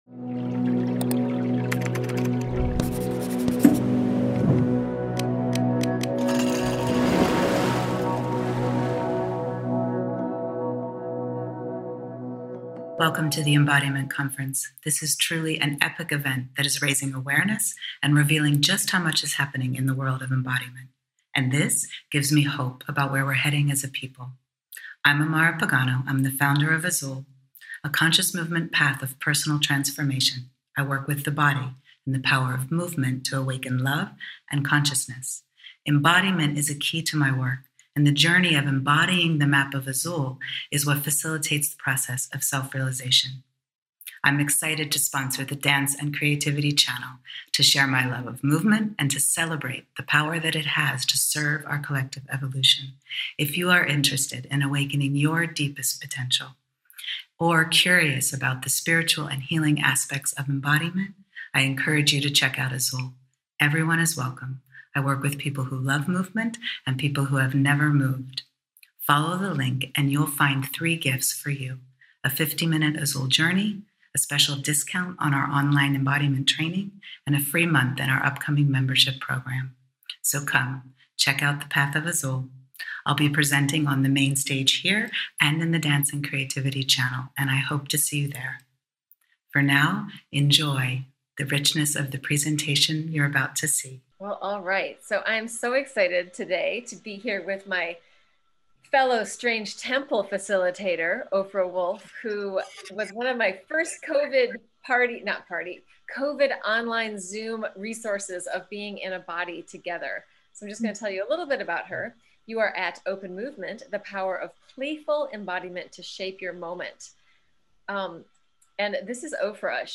with live music!
Guided Practices